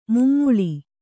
♪ mummuḷi